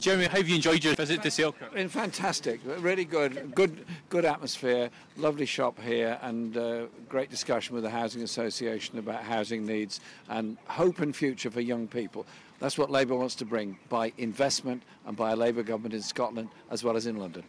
LISTEN: Labour leader Jeremy Corbyn reveals his plans to build a better Britain